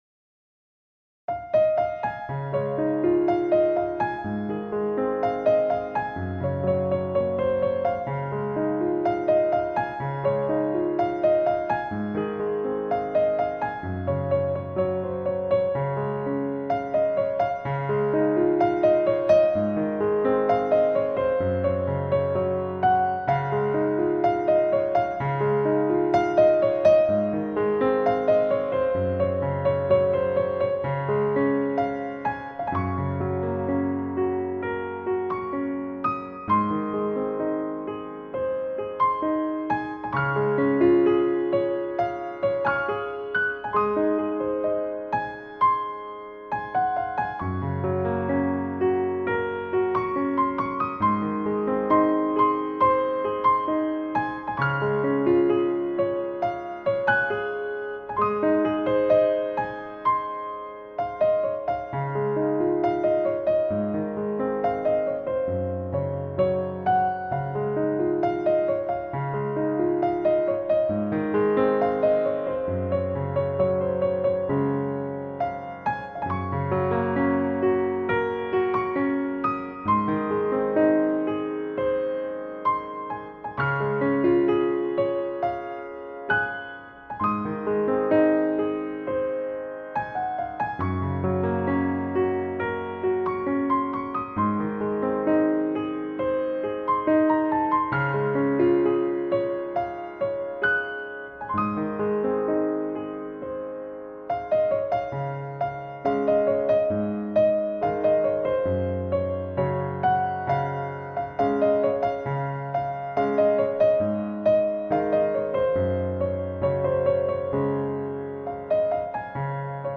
钢琴曲